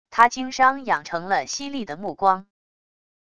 他经商养成了犀利的目光wav音频生成系统WAV Audio Player